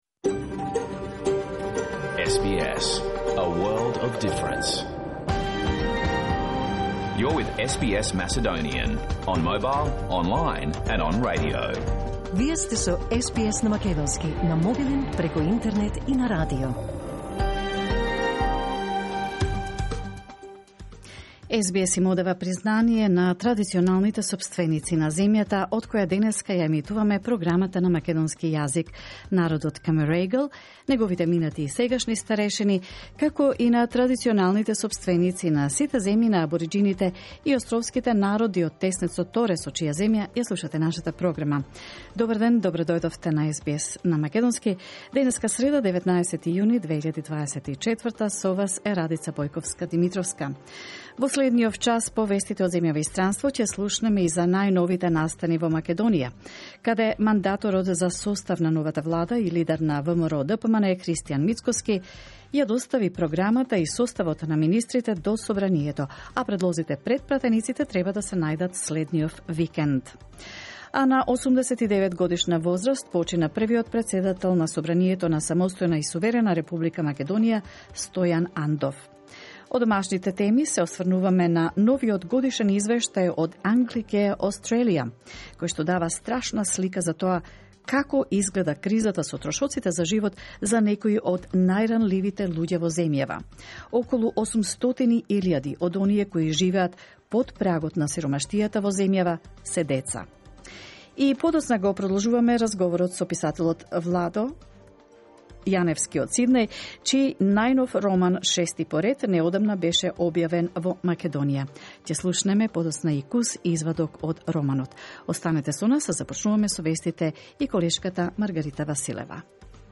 SBS Macedonian Program Live on Air 19 June 2024